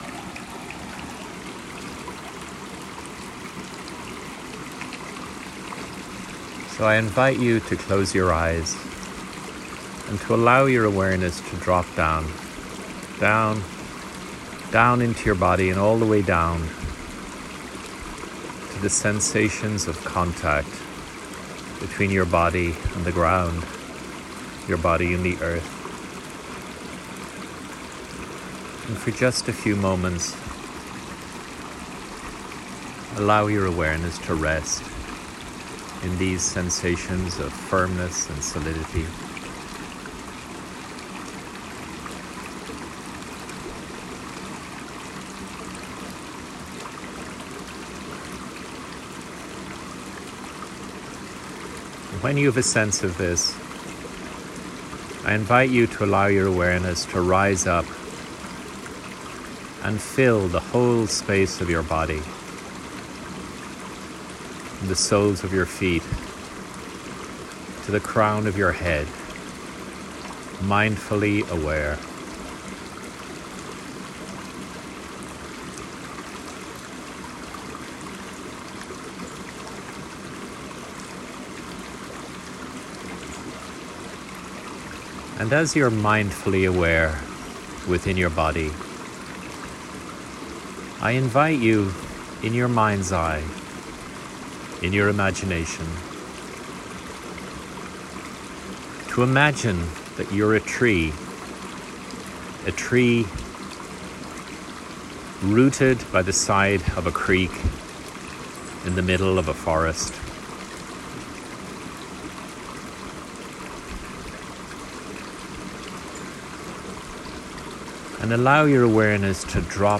Find a quiet place to listen to this short meditation.